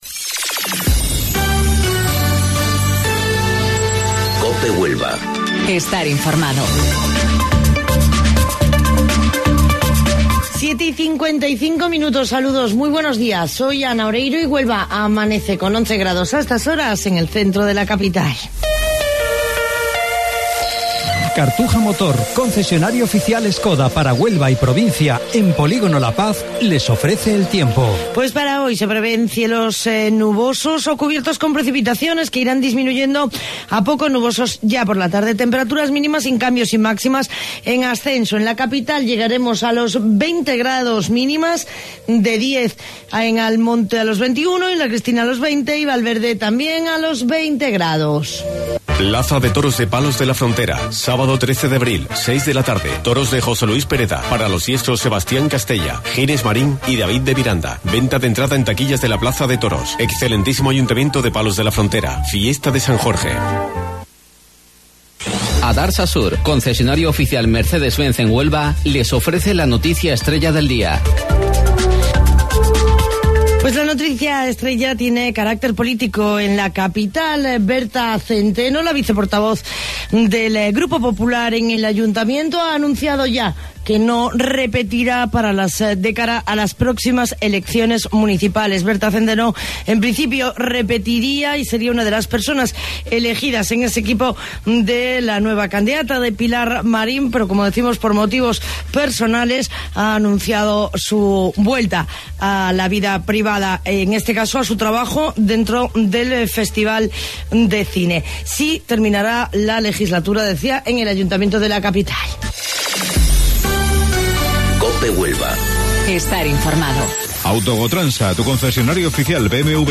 AUDIO: Informativo Local 07:55 del 8 de Abril